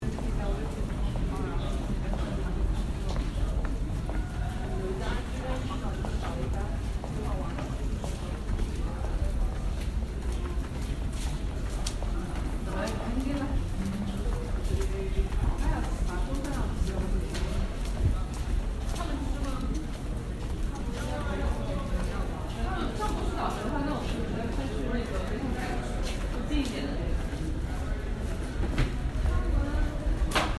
Footsteps – Page 2 – Hofstra Drama 20 – Sound for the Theatre
Walking-Across-The-Unispan.mp3